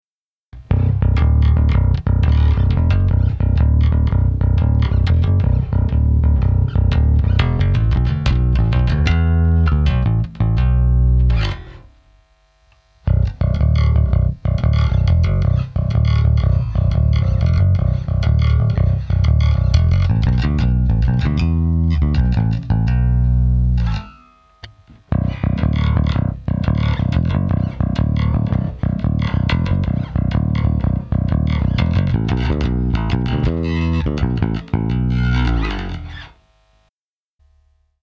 Cort GB35J - пятиструнный джазбас.
Звукосниматели не вынимал, но по идее и по звуку там керамические магниты, но несмотря на это и на агатис, звучит Cort GB35J очень прилично.
fingers.mp3